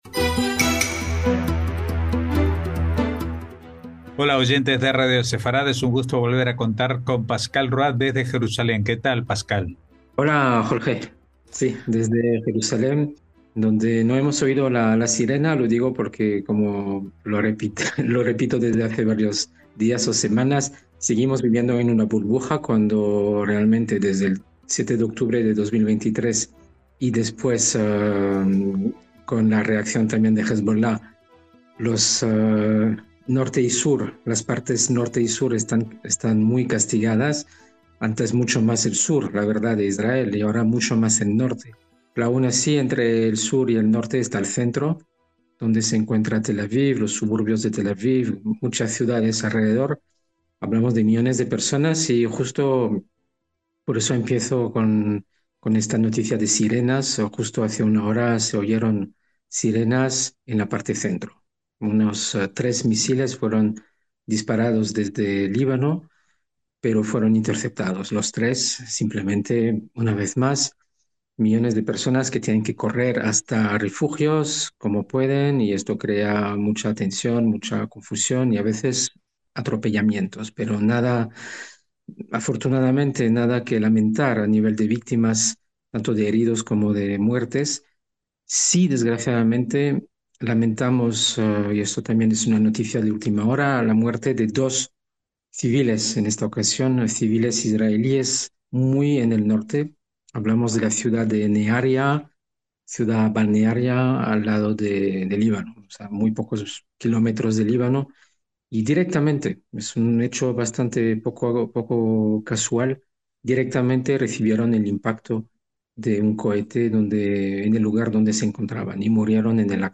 NOTICIAS CON COMENTARIO A DOS - En las últimas horas se ha producido la muerte por impacto directo de un cohete de dos civiles israelíes en la zona norte del país (en la localidad de Nahariya, en la imagen). Aunque se escucha un rumor creciente de negociaciones de cese de fuego en la zona, el lunes fue disparada una salva de unos 90 misiles sincronizados desde cuatro o cinco lugares geográficos distintos en Líbano.